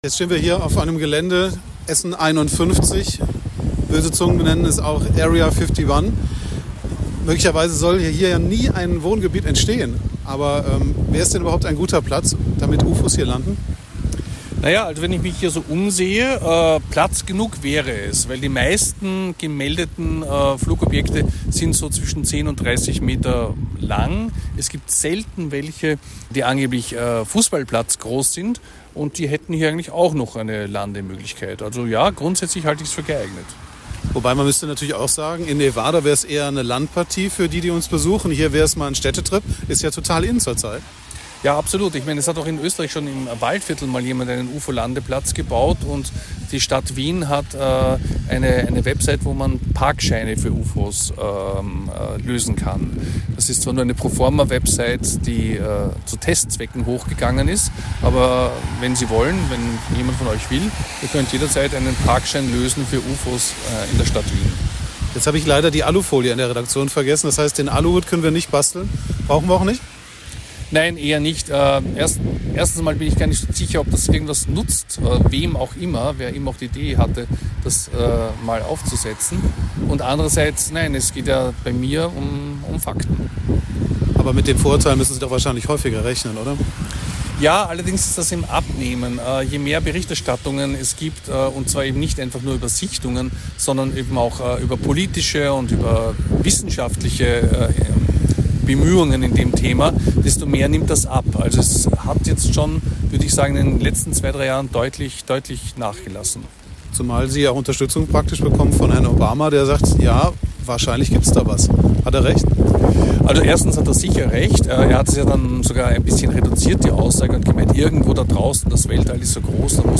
Auch ist er sich nicht zu fein sich mit uns bei strömendem Regen und einem unangenehmen Wind an der Baustelle von Essen 51 zu treffen.
im Gespräch mit dem Ufo-Experten